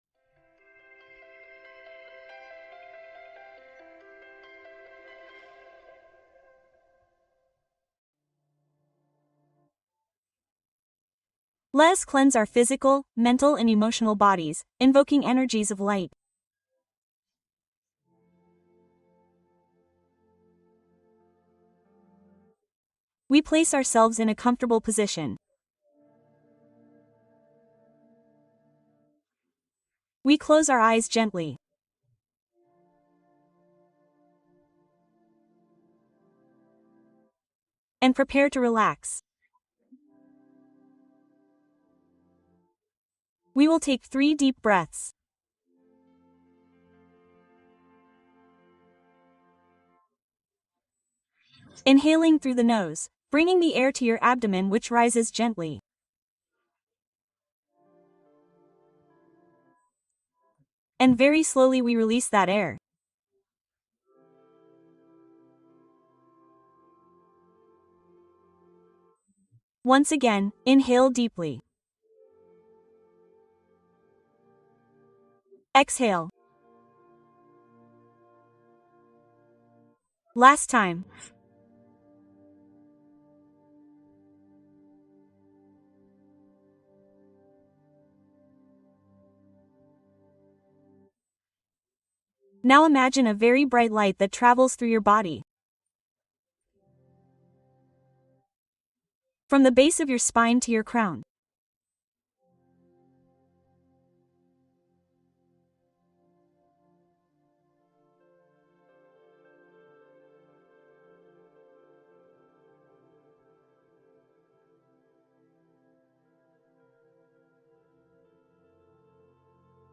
Meditación guiada de los siete arcángeles para protección y sabiduría